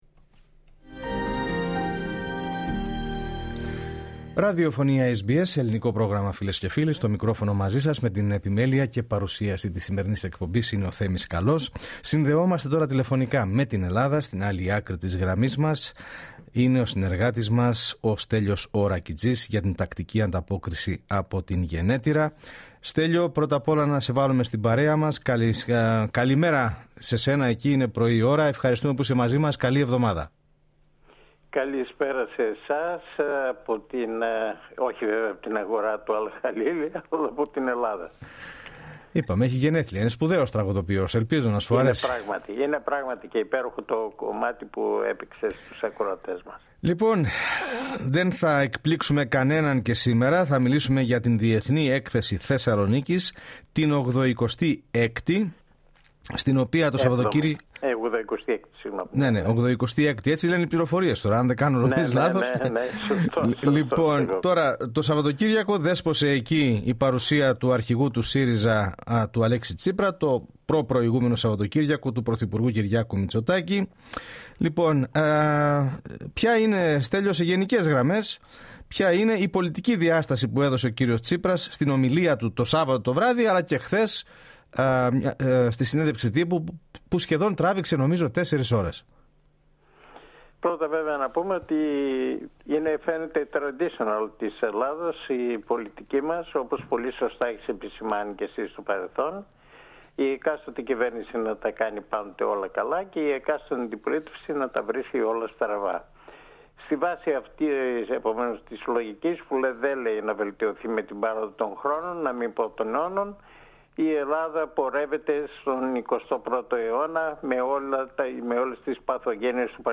ανταποκριση-απο-ελλαδα-19-σεπτεμβριου.mp3